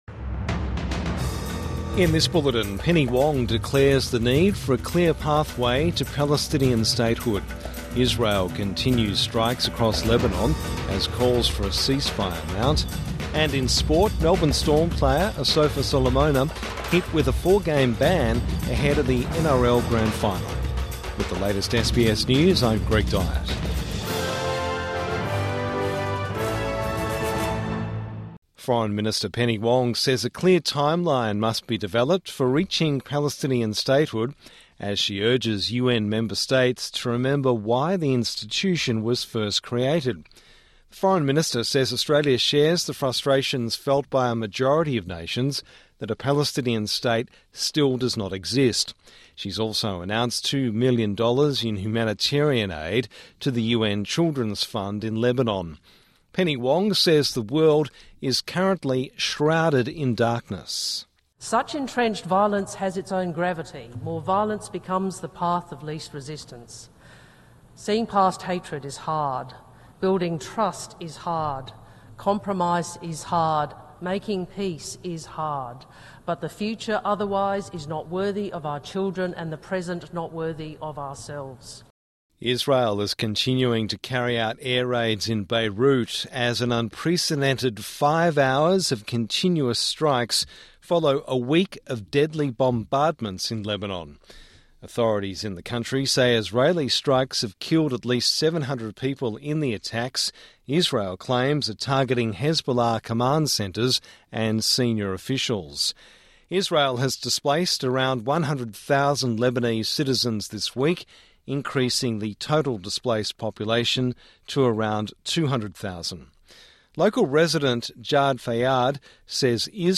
Evening News Bulletin 28 September 2024